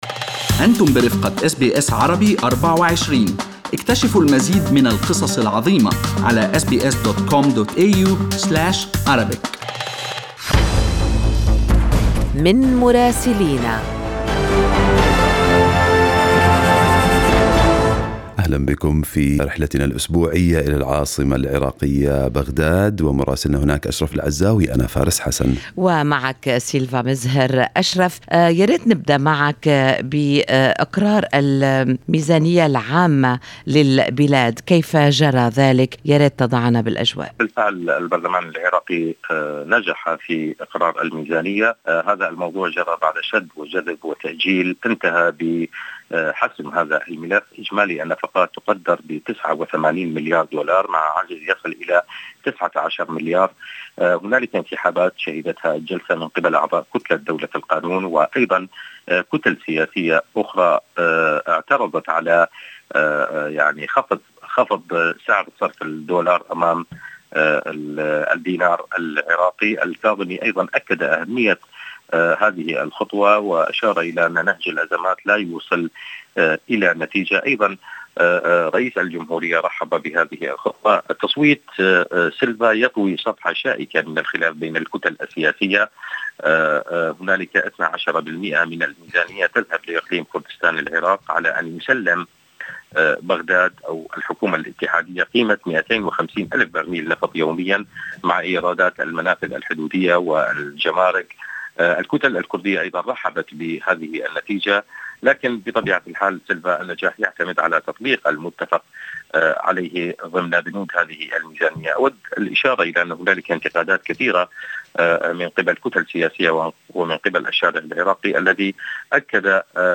يمكنكم الاستماع إلى تقرير مراسلنا في بغداد بالضغط على التسجيل الصوتي أعلاه.